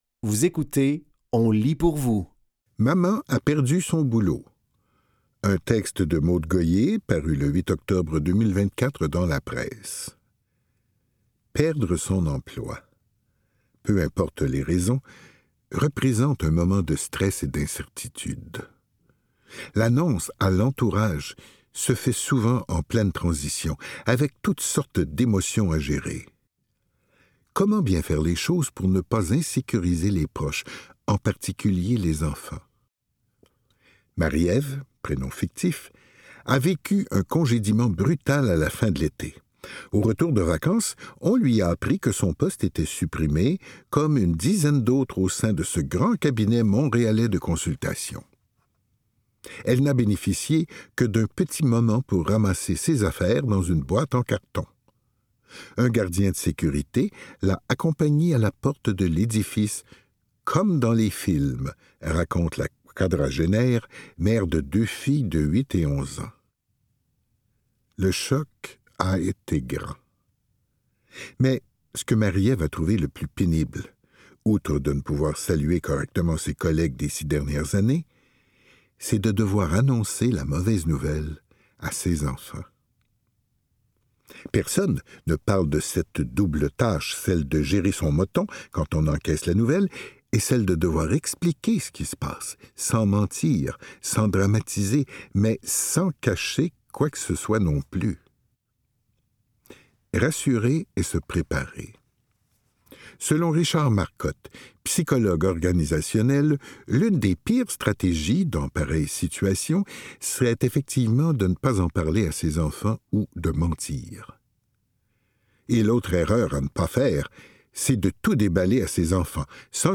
Dans cet épisode de On lit pour vous, nous vous offrons une sélection de textes tirés des médias suivants : La Presse, Le Devoir et Fugues.